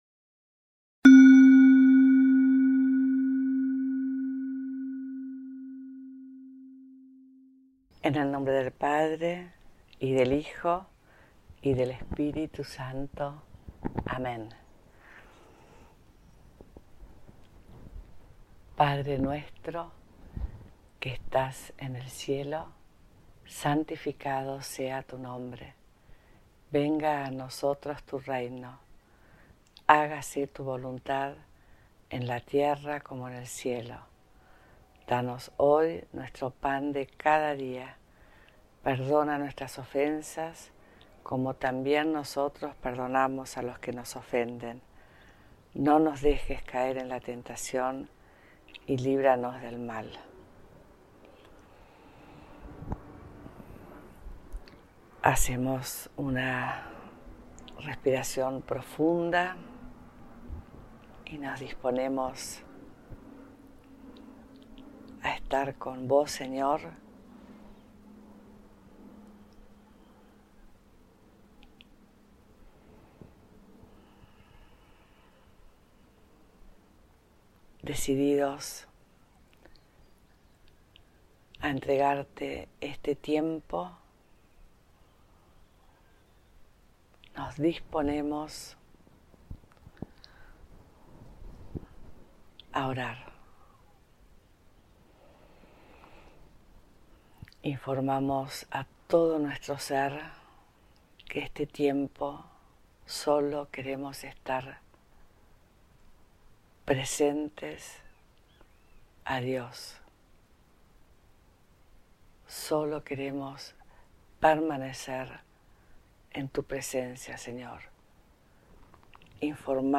Audio-1-Oracion-guiada_Recogimiento_completo.mp3